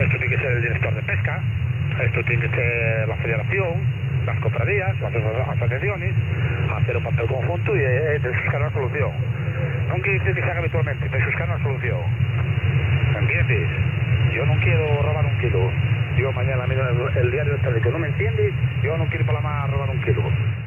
SSB
As an example of these transmissions, we show here the transmissions of a group of Spanish fishers on the 40 meters amateur band
SSB (J3E) Non-amateur comms (intruders). Spanish fishers on the 40 m amateur band
SSB-Spanish-fishers.wav